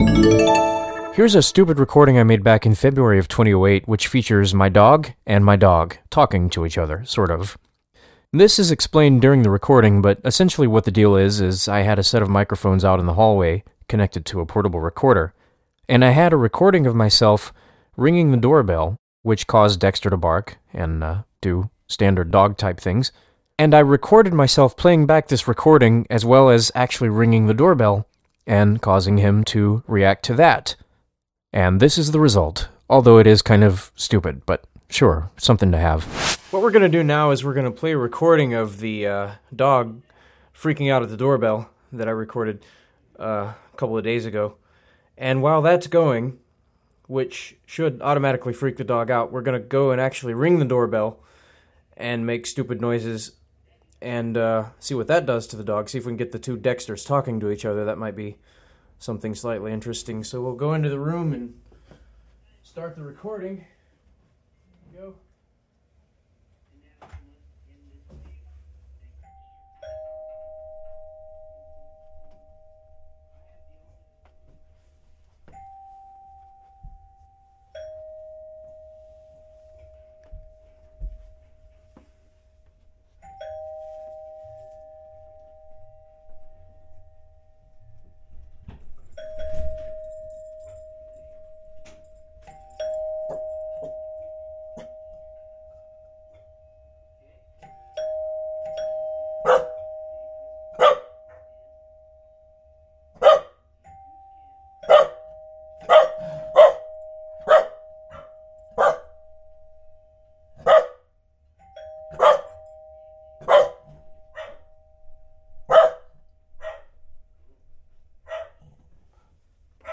This was achieved by playing back a previous recording of the dog reacting to the doorbell, while ringing the doorbell in real time on the recording itself. The effect, naturally, is the dog talking to himself, although he didn't react to the prior recording. I didn't think that would happen, which is why I added the live element to get the desired effect.